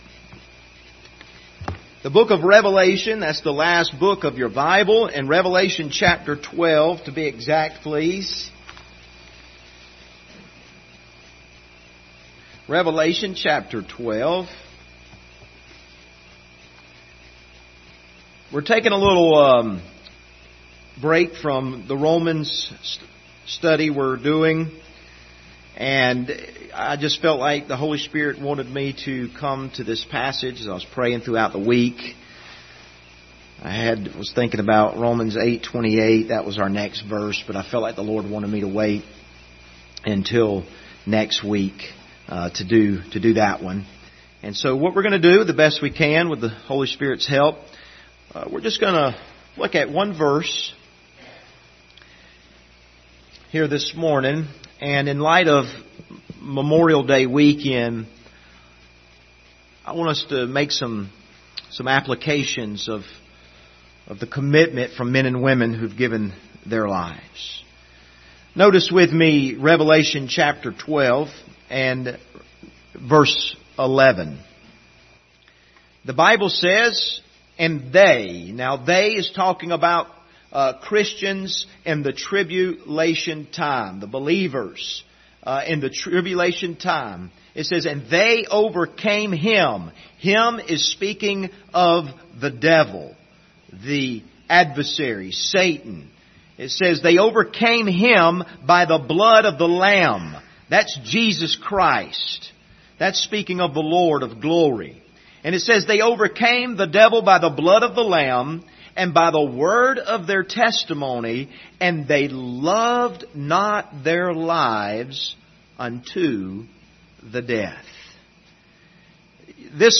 Passage: Revelation 12:11 Service Type: Sunday Morning